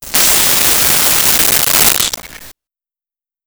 Snake Hiss 01
Snake Hiss 01.wav